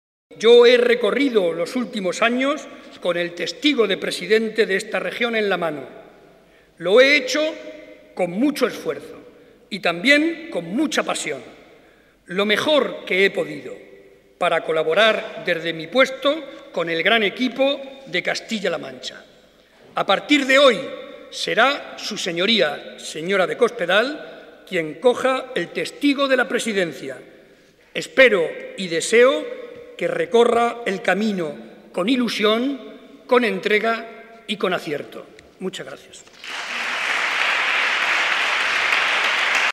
Cortes de audio de la rueda de prensa
audio_Barreda_Discurso_Debate_Investidura_210611_6